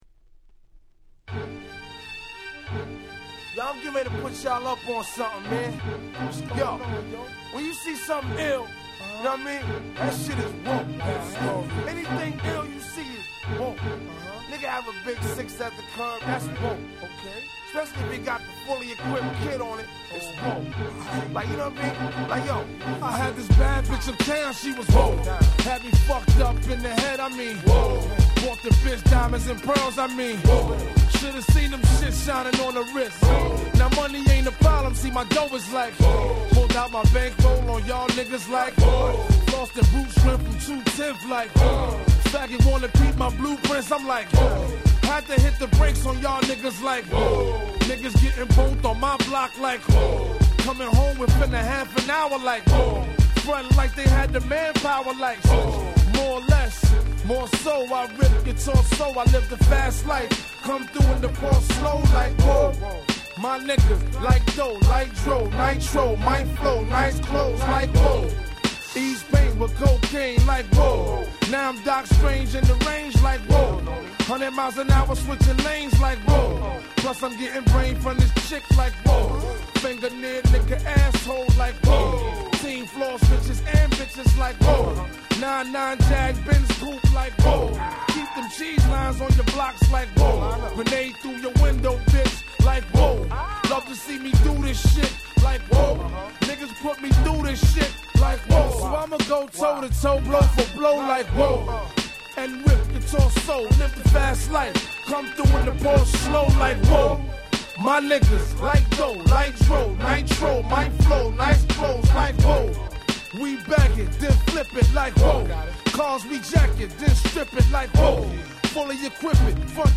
00' Big Hit Hip Hop !!
当時のNYのBlazin'な空気感をヒシヒシと感じさせる問答無用のHip Hop Classicsです。